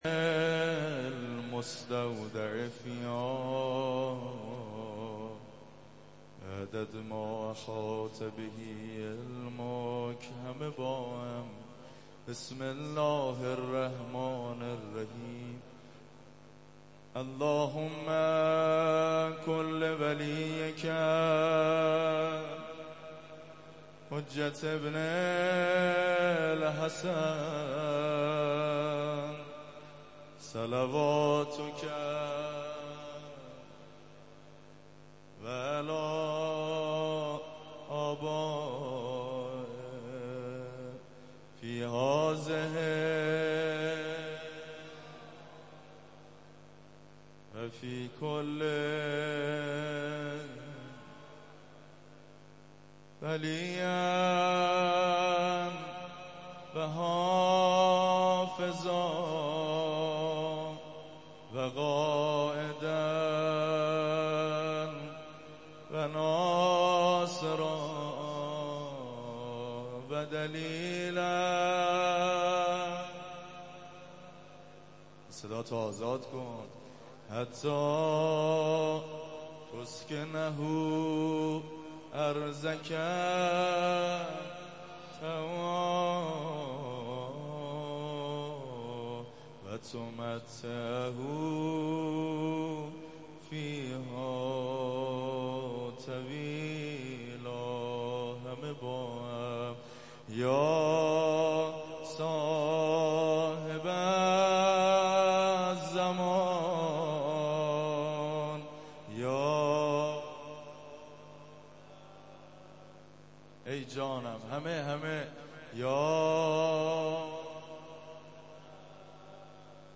شب شهادت امام صادق (ع)